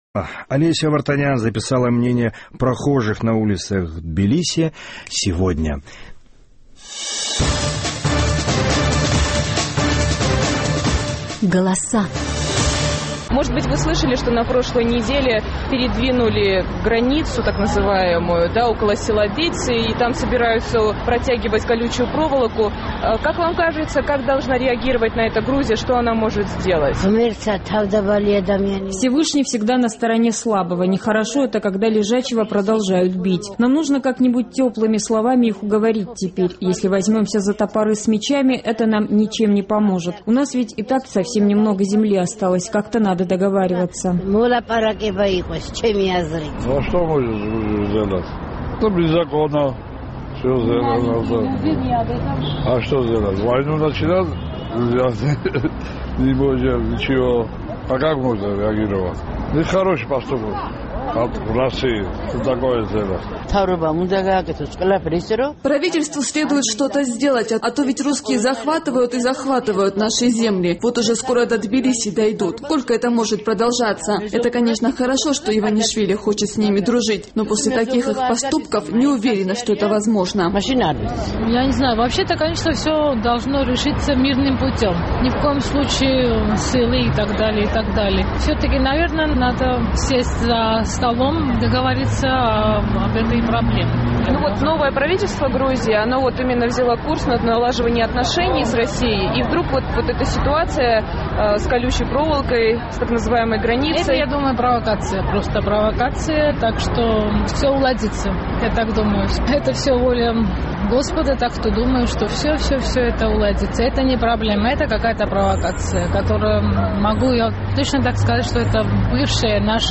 На прошлой неделе около села Дици в Горийском районе сдвинули т.н. границу, там собираются тянуть колючую проволоку. Как к этому факту относятся жители грузинской столицы, выясняла наш тбилисский корреспондент.